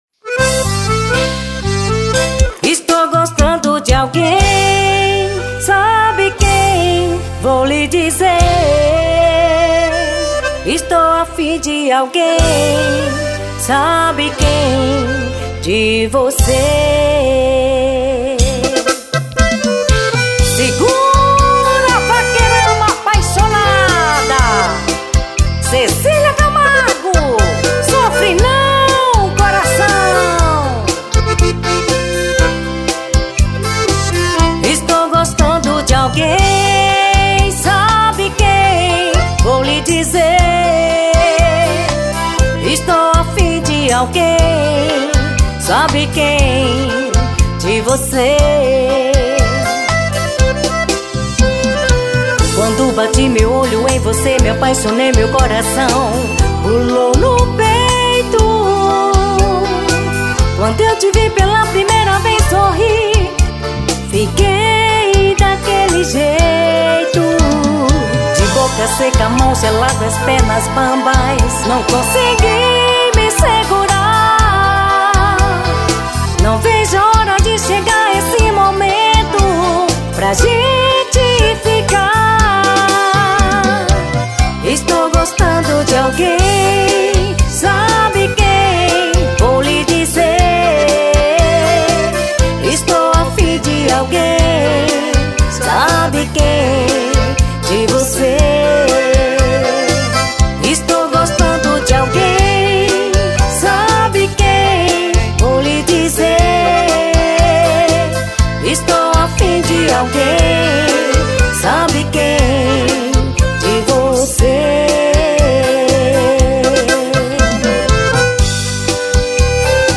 Segmento: Música
Forró e vaquejada.
VOCALISTA
TECLADO